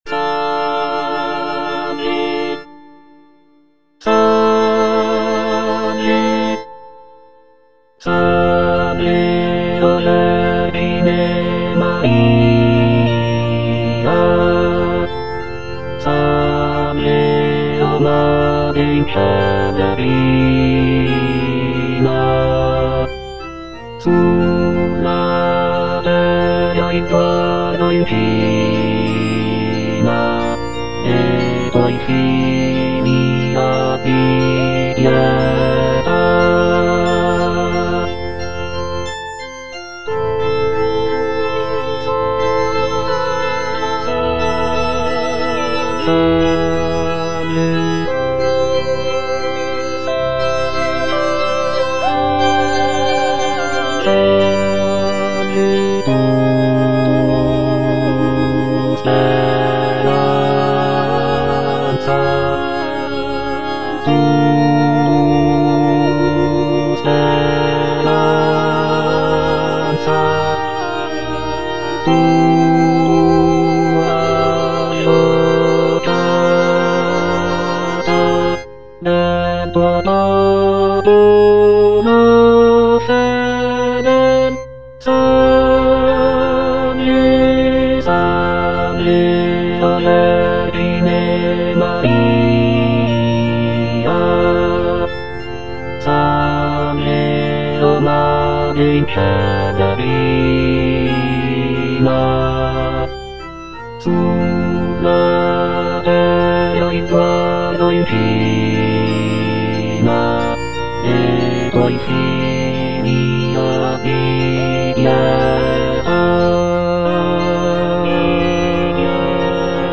Bass (Emphasised voice and other voices)
is a choral piece